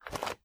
STEPS Dirt, Walk 18.wav